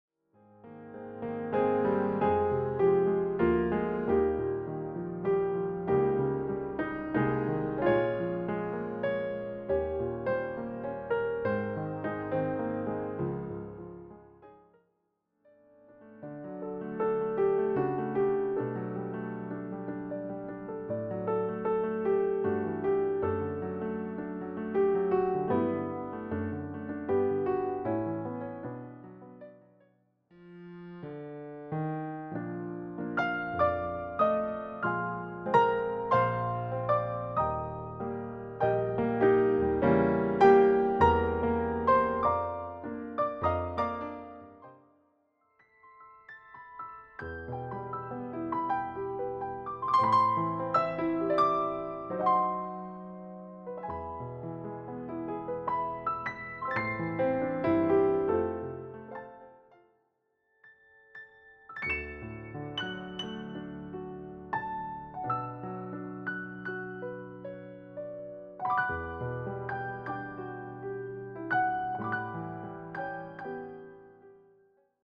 " leaving things on a calm, unhurried note.